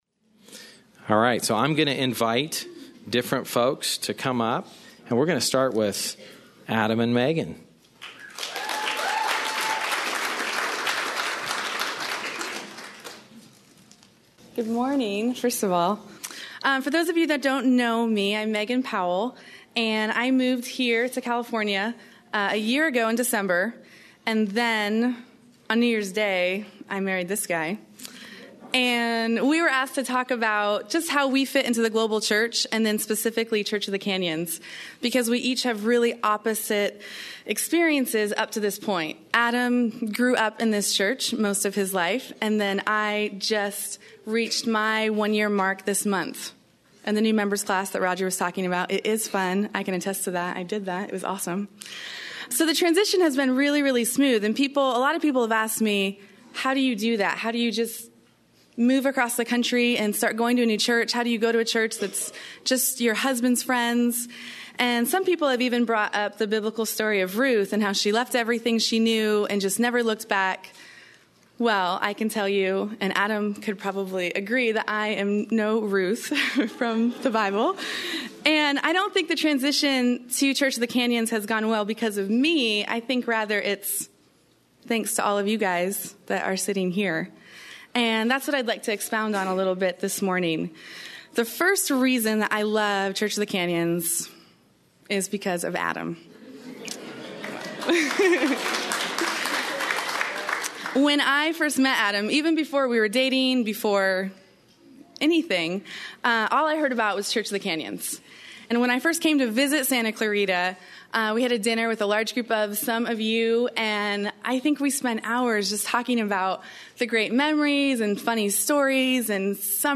Church of the Canyons - Sermons - Santa Clarita - Evangelical Free